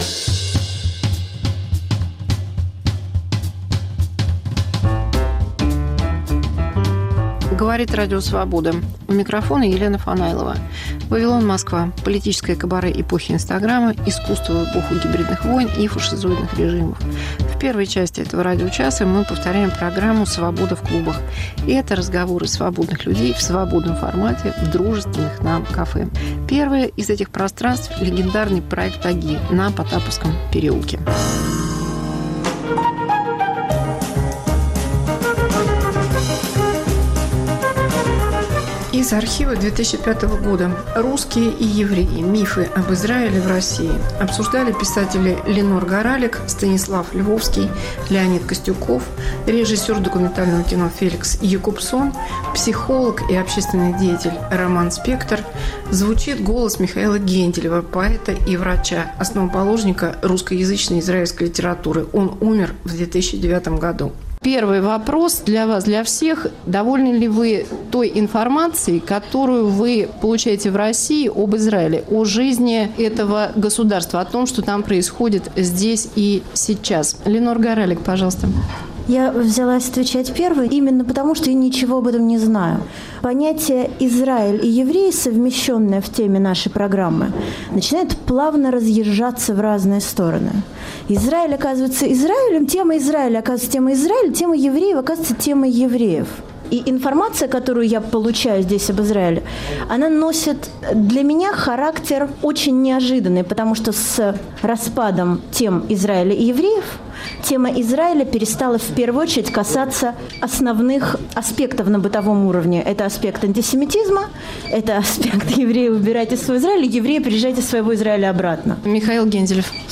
Елена Фанайлова в политическом кабаре эпохи инстаграма. Мегаполис Москва как Радио Вавилон: современный звук, неожиданные сюжеты, разные голоса. 1.